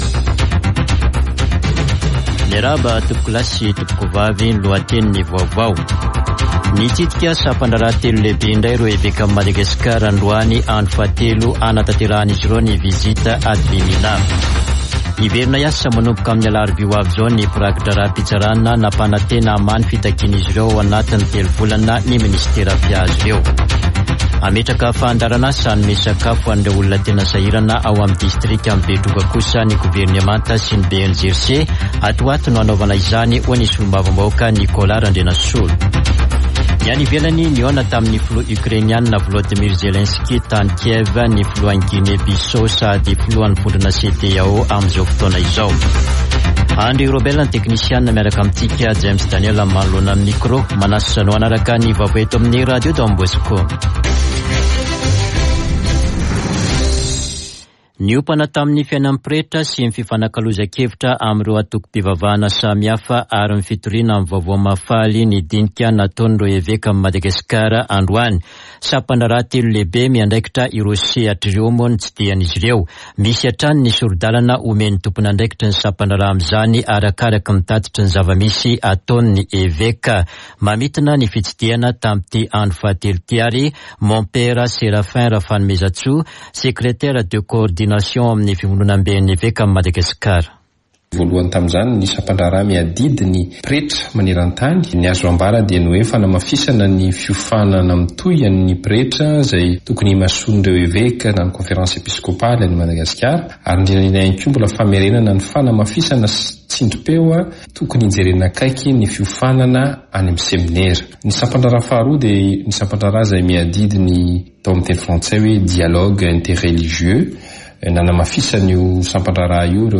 [Vaovao hariva] Alarobia 26 ôktôbra 2022